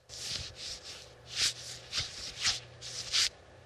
Звуки пота: протирание влаги со лба ладонью